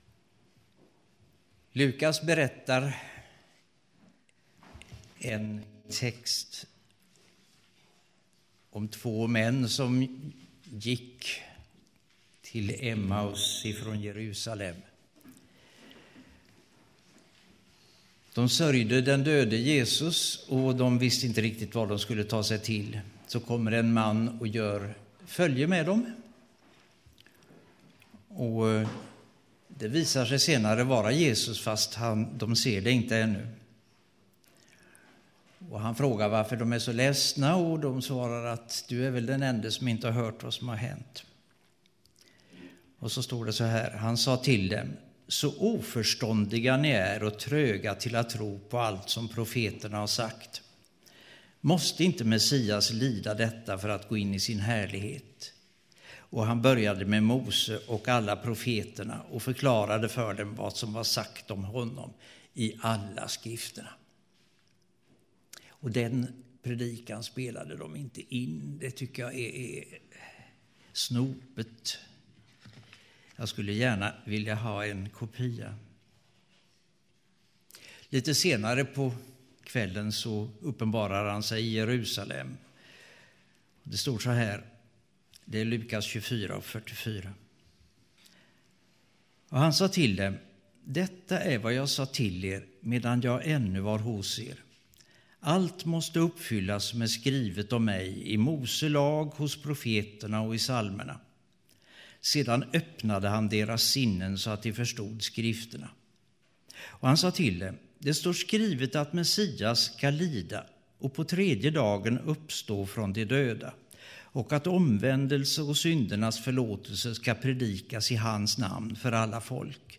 2011-04-24 Predikan av